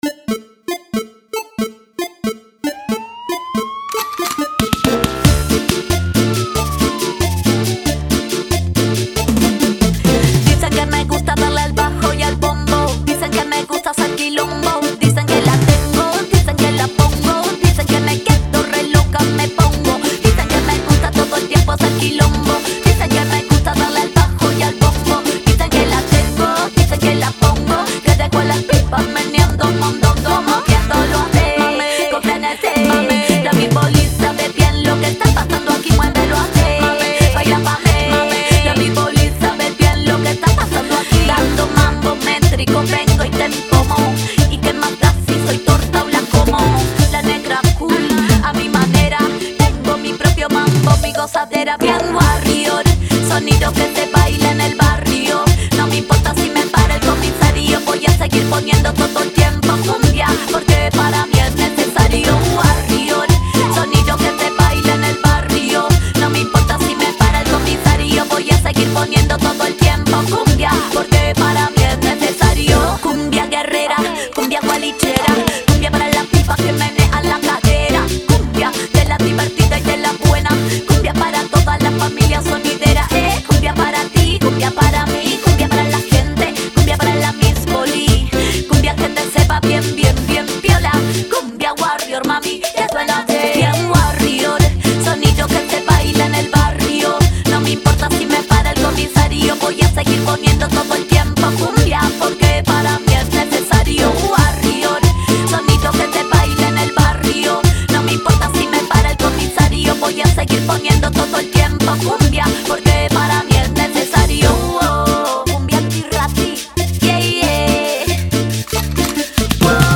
the singer, DJ, MC and producer
creating a hot dancing atmosphere
Hyper-danceable and adaptable